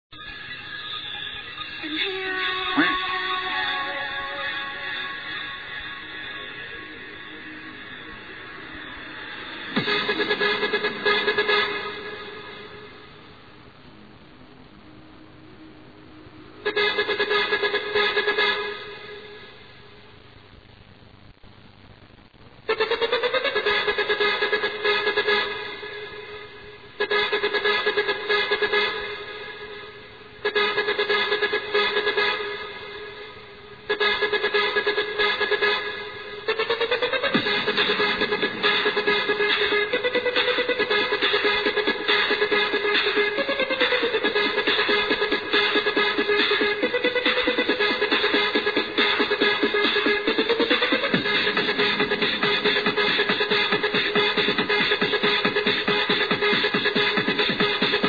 the girl who talks